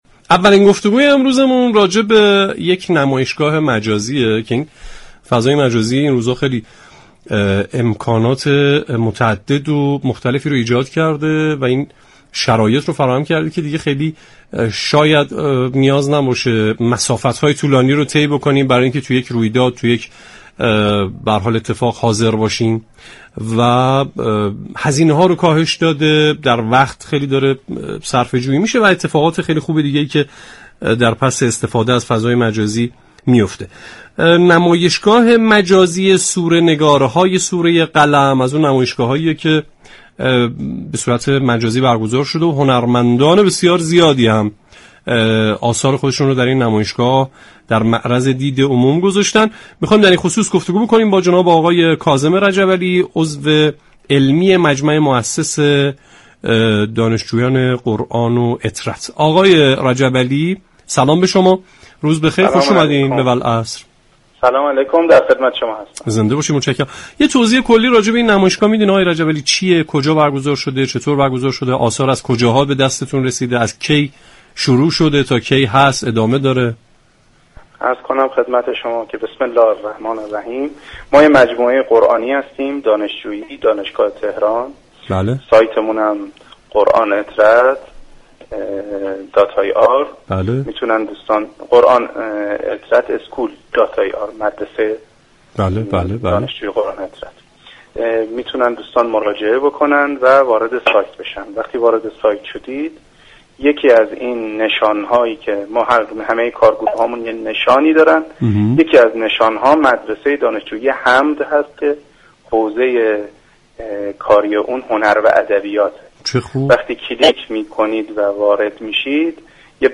در گفتگو با برنامه والعصر رادیو قرآن